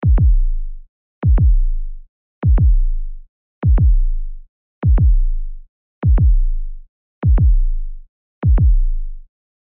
دانلود آهنگ ضربان قلب منظم و سریع از افکت صوتی انسان و موجودات زنده
دانلود صدای ضربان قلب منظم و سریع از ساعد نیوز با لینک مستقیم و کیفیت بالا
جلوه های صوتی